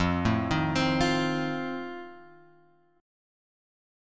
F7 chord {1 0 1 x 1 1} chord